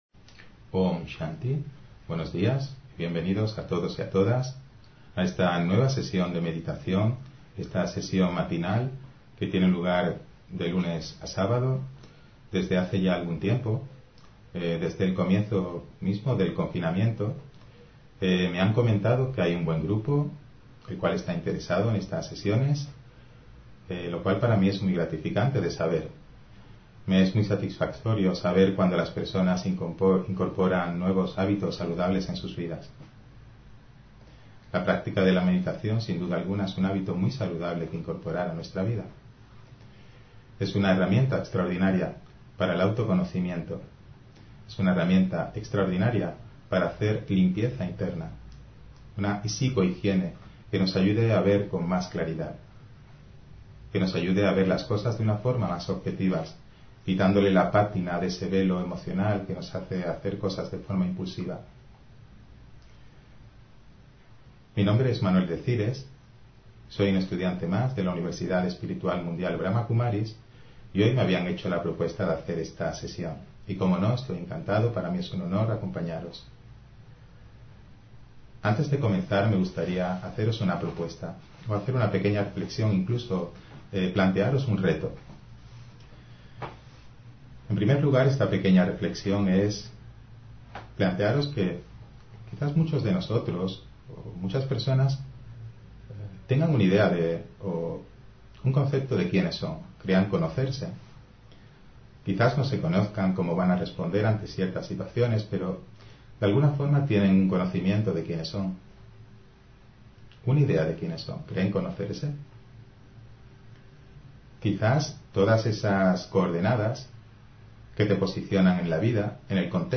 Meditación Raja Yoga: 8 Poderes: Empaquetar (16 Junio 2020) On-line desde Canarias